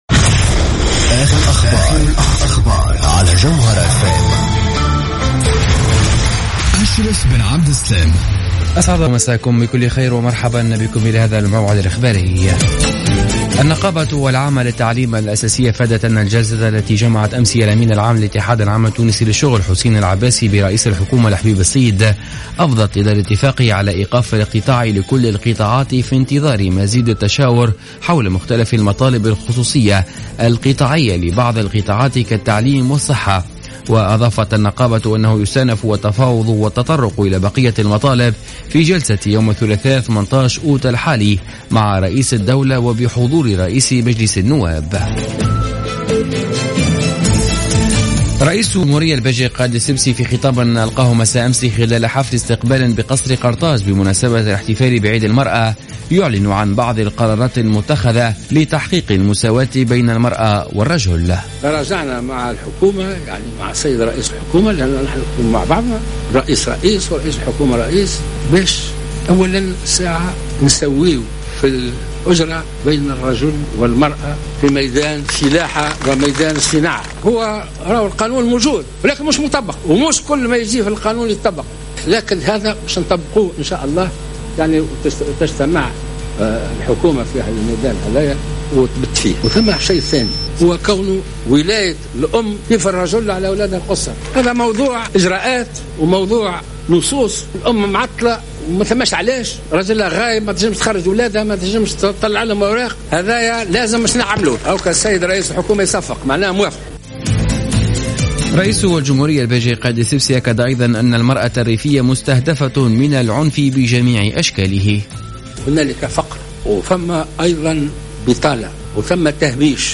نشرة أخبار منتصف الليل ليوم الجمعة 14 أوت 2015